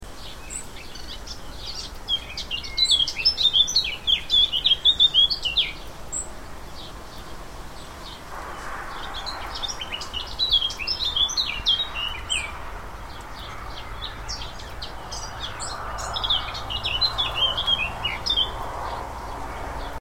recording of a Blackcap singing in the garden. (mp3 file, 313kB, 20sec, stereo)
blackcap_01.mp3